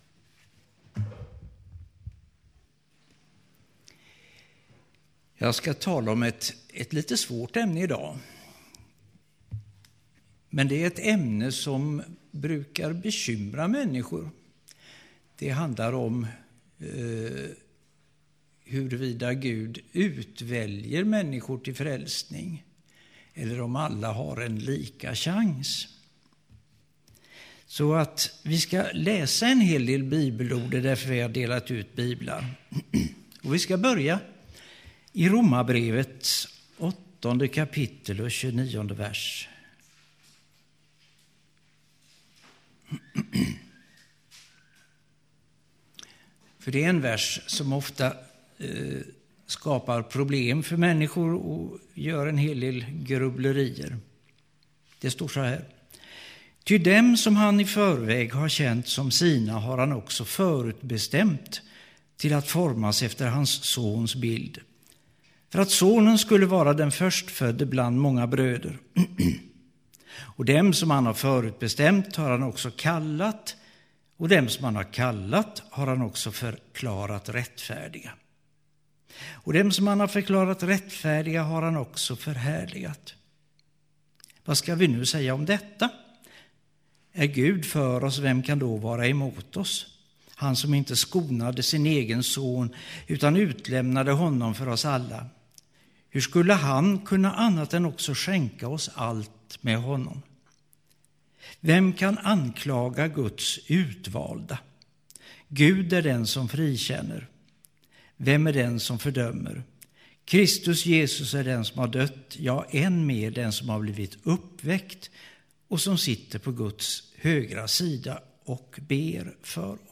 Predikan